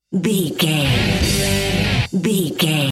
Aeolian/Minor
drums
electric guitar
bass guitar
hard rock
aggressive
energetic
intense
nu metal
alternative metal